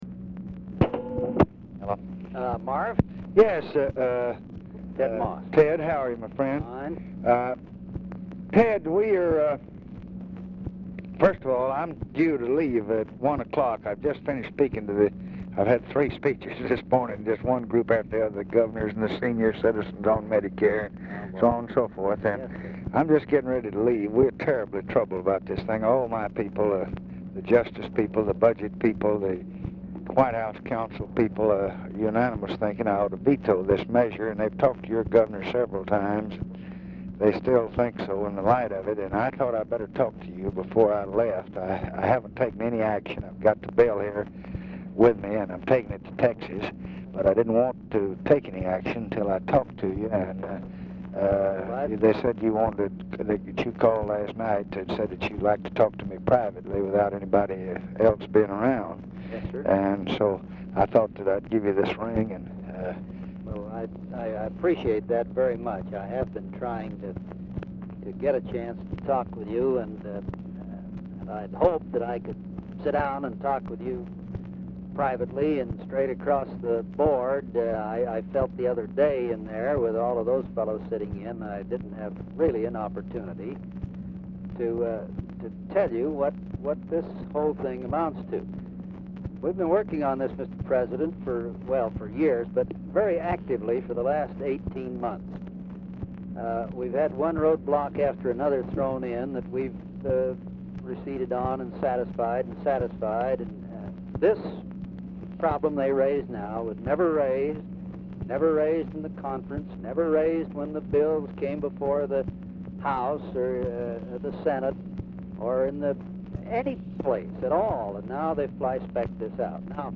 Telephone conversation # 10208, sound recording, LBJ and FRANK "TED" MOSS, 6/3/1966, 1:04PM | Discover LBJ
Format Dictation belt
Location Of Speaker 1 Oval Office or unknown location
Specific Item Type Telephone conversation